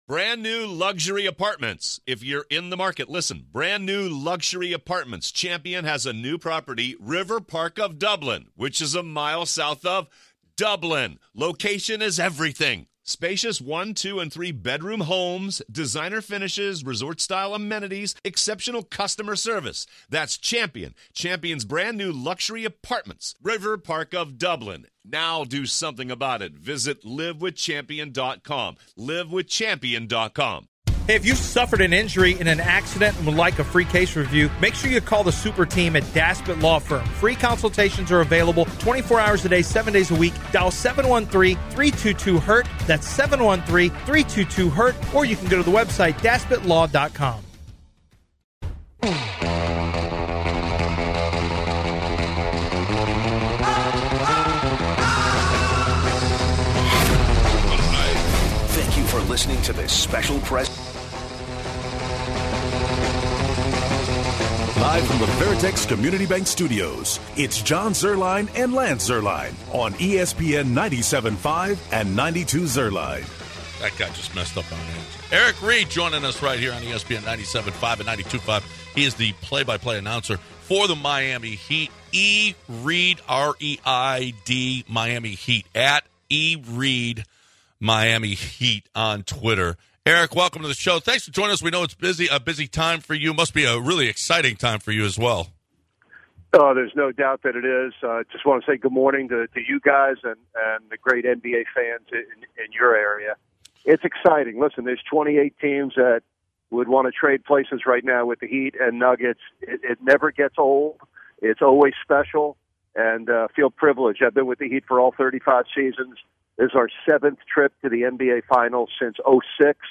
In this episode callers also give us their take on current news with our Houston teams.